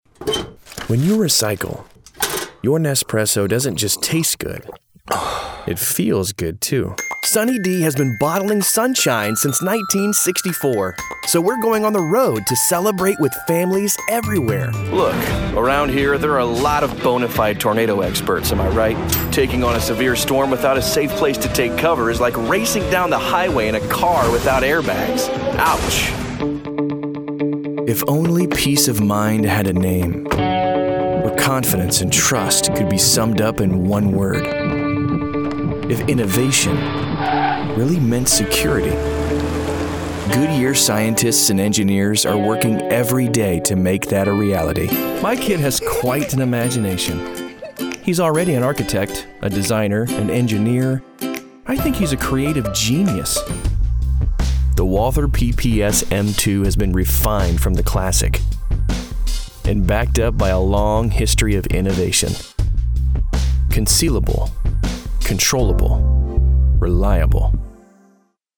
Mature Adult, Teenager, Adult, Young Adult
Has Own Studio
standard us
commercial
authoritative
cool